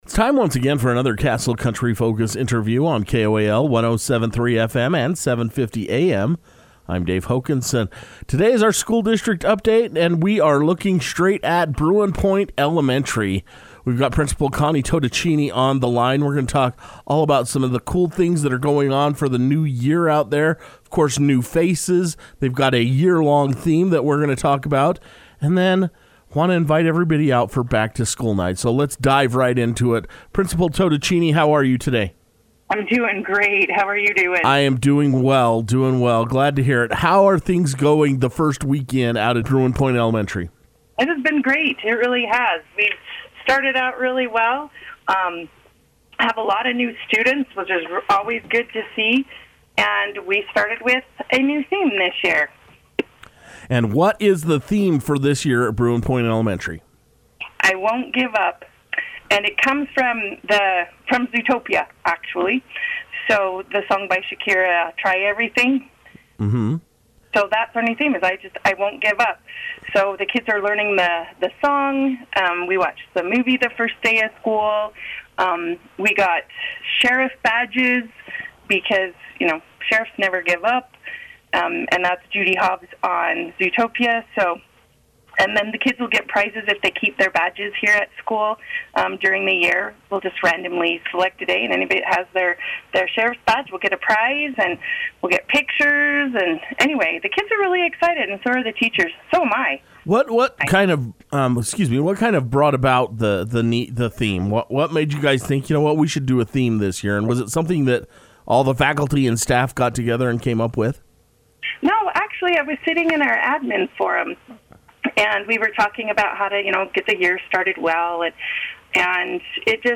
took time to speak over the telephone about the school’s yearly theme and new faculty.